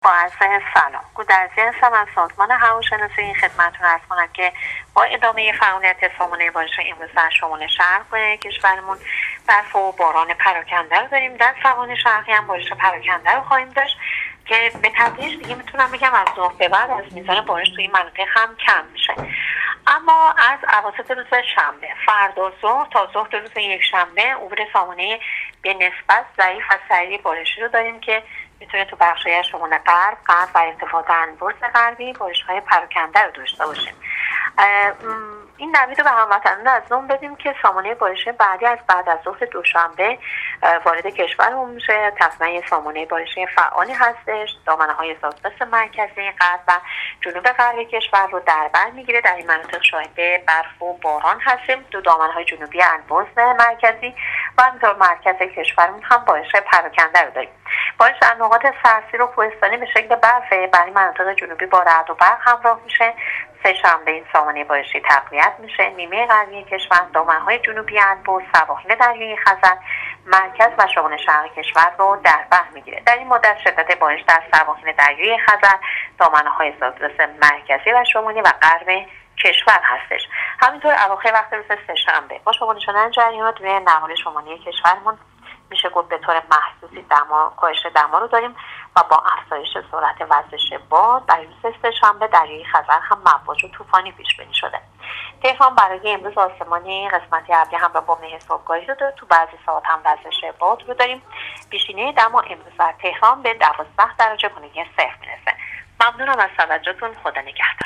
گزارش رادیو اینترنتی از آخرین وضعیت آب و هوای دوم اسفندماه ۱۳۹۸